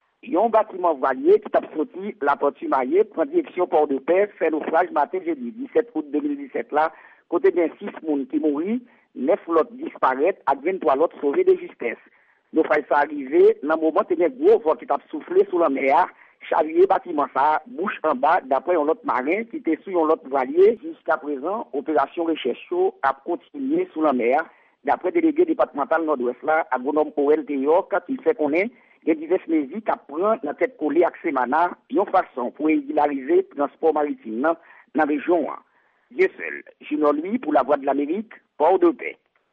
Yon repòtaj korespondan Lavwadlamerik